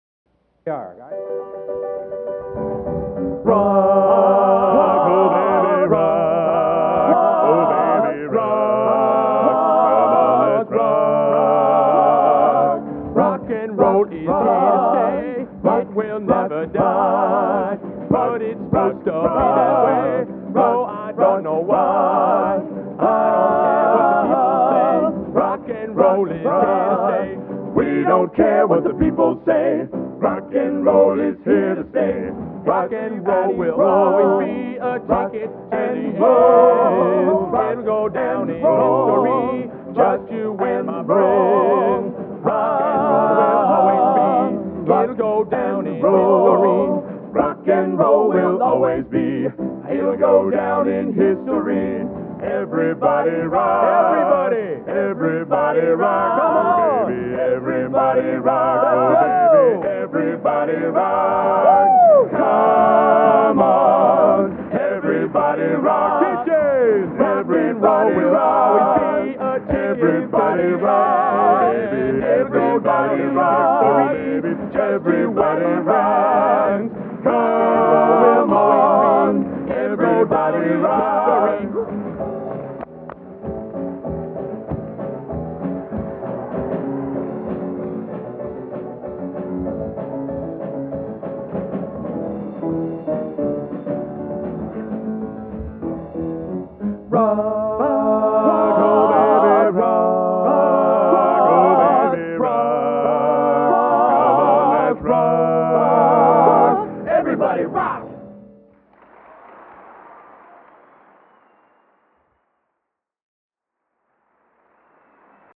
Collection: End of Season, 1987
Location: West Lafayette, Indiana